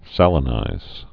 (sălə-nīz)